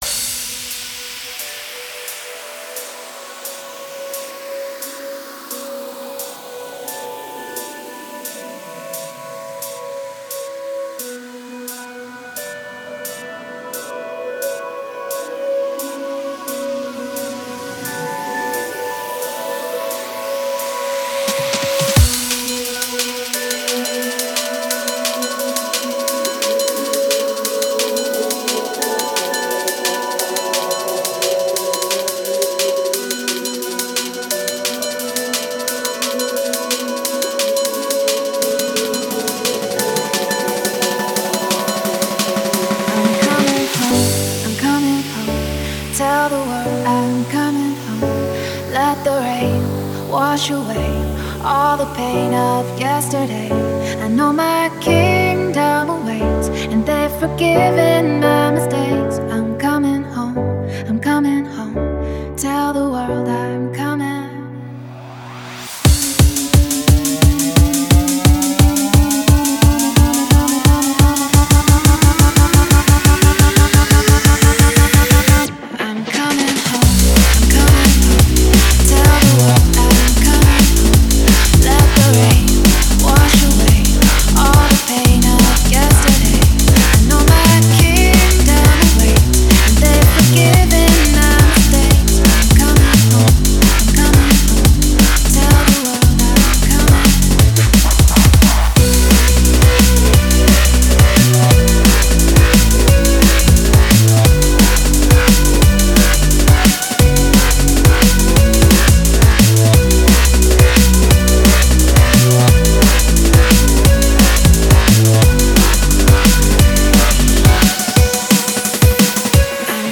Клубная музыка
Драм-н-бэйс музыка